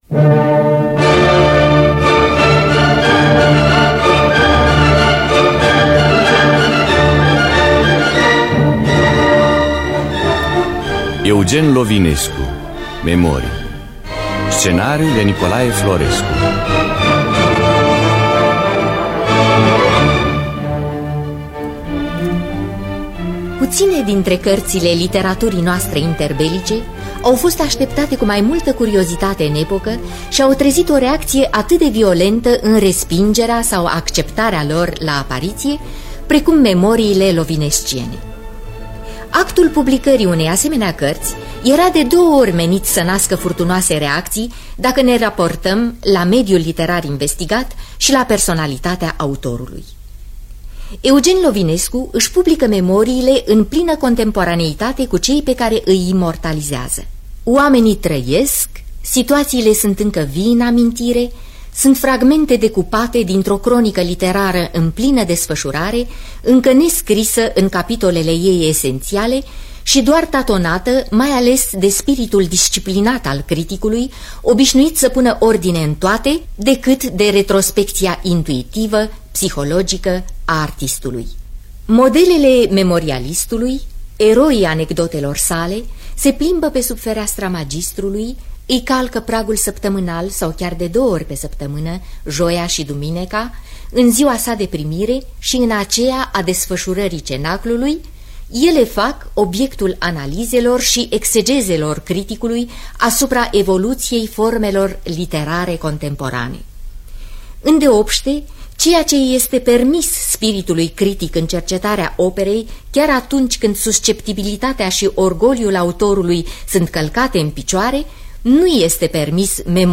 Biografii, Memorii: Eugen Lovinescu – Memorii (1973) – Teatru Radiofonic Online